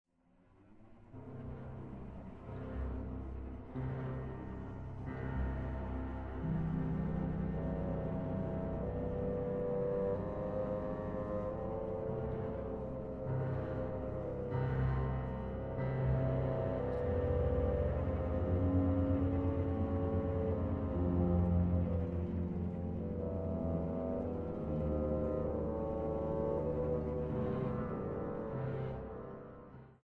para gran orquesta
Andante – Allegro molto